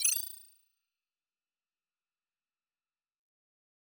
Futurisitc UI Sound 14.wav